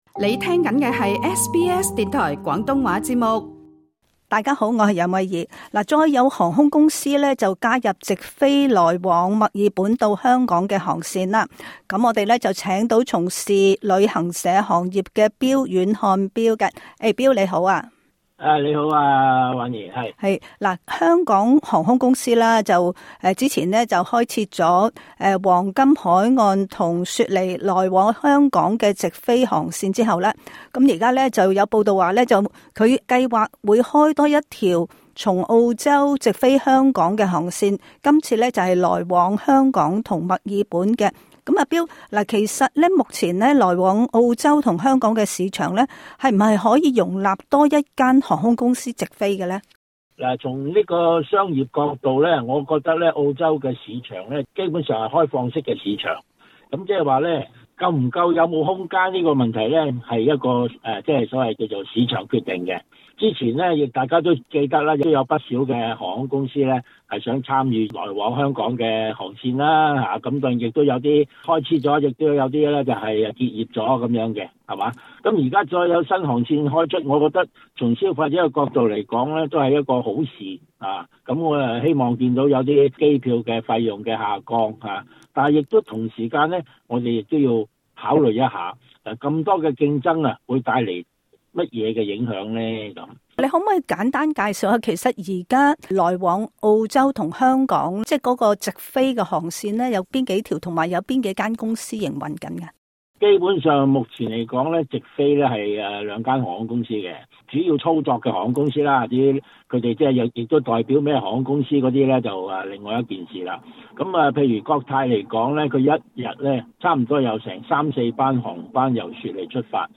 詳情請聽這一輯訪問。